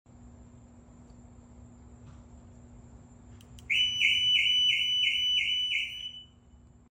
Just in case you were wondering what panic alarm sounds like on a Audi e-tron.